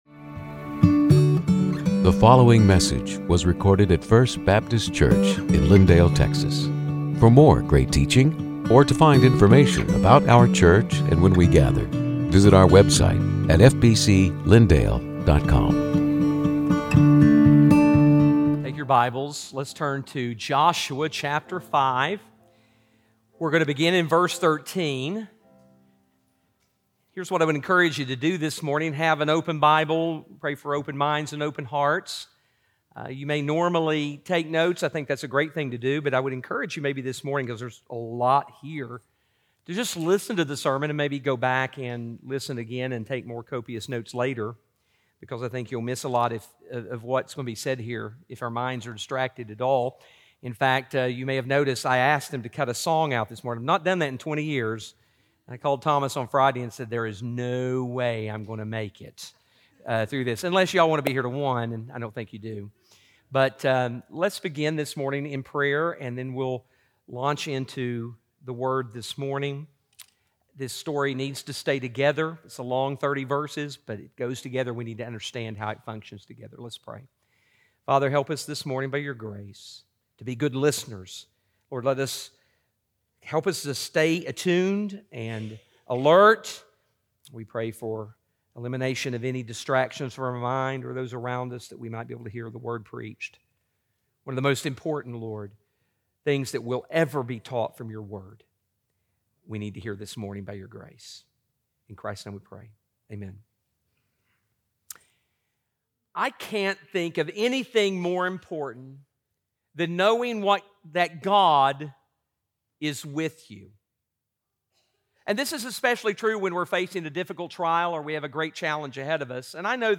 Sermons › Joshua 5:13-6:27